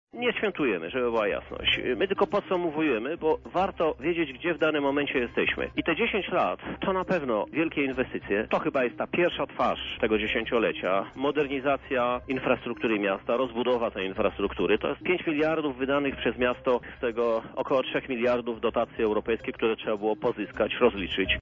O ostatnich 12 miesiącach widzianych oczyma samorządowca mówił prezydent Lublina Krzysztof Żuk, który był gościem Porannej  Rozmowy Radia Centrum.